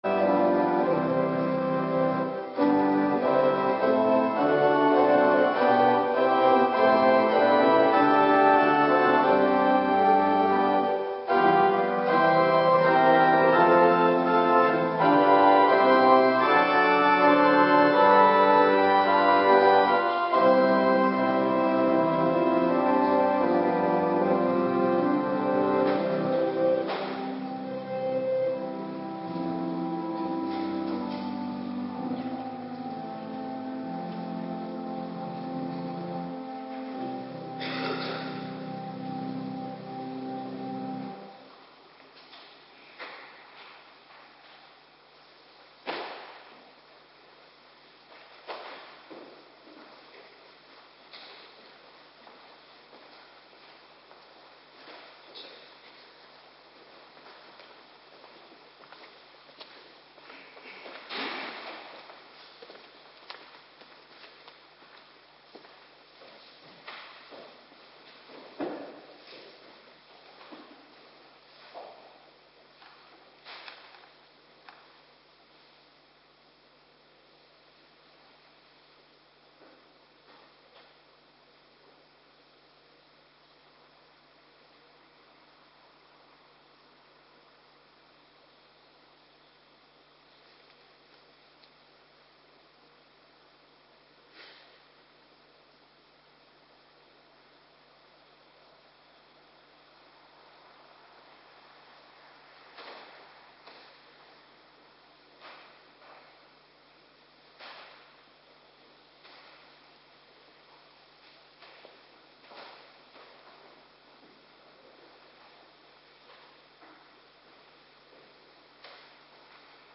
Avonddienst Internetuitzending
Locatie: Hervormde Gemeente Waarder